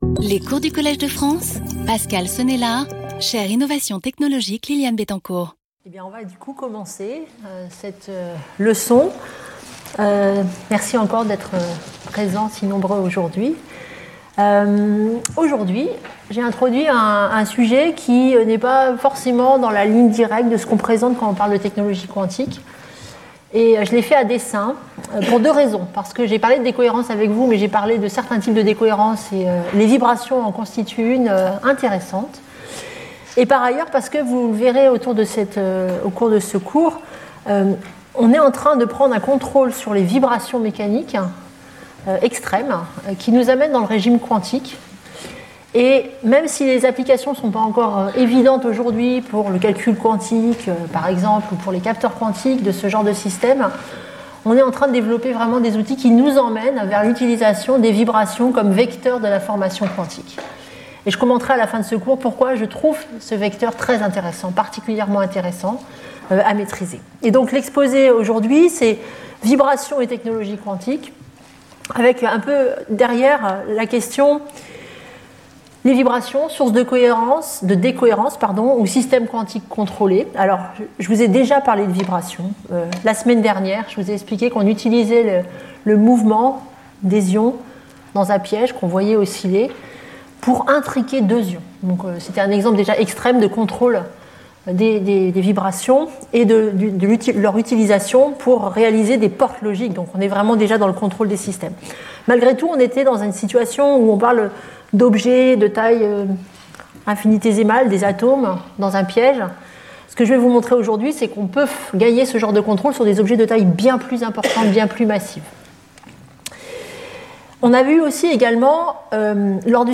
Ce cours introduira ces deux facettes des vibrations ou phonons dans les technologies quantiques émergentes. Nous verrons par exemple comment réduire fortement le couplage d’un qubit à l’état solide aux phonons afin de préserver la cohérence quantique. Nous introduirons également le domaine de l’optomécanique quantique où le mouvement de systèmes macroscopiques est contrôlé optiquement jusqu’à atteindre le régime quantique.